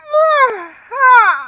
0D_toad_yawning.aiff